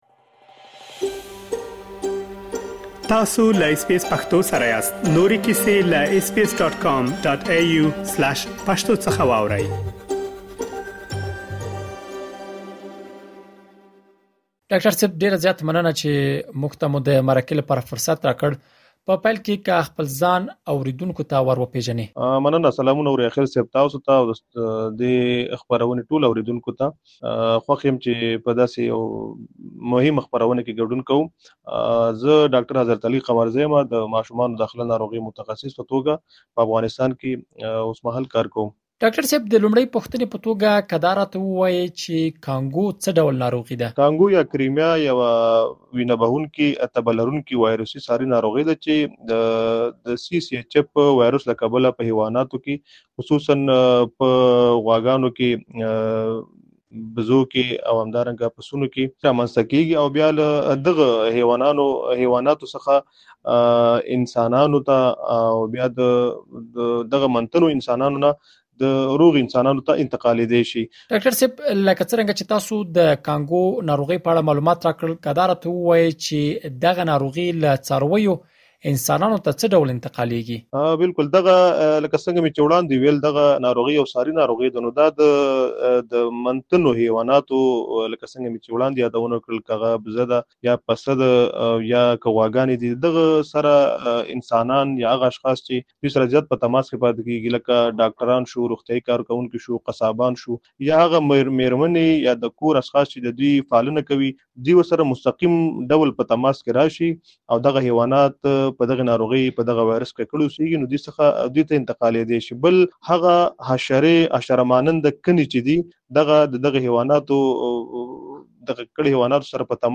تاسو کولی شئ لا ډېر معلومات په ترسره شوې مرکې کې واورئ.